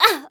qyh受伤2.wav 0:00.00 0:00.32 qyh受伤2.wav WAV · 28 KB · 單聲道 (1ch) 下载文件 本站所有音效均采用 CC0 授权 ，可免费用于商业与个人项目，无需署名。
人声采集素材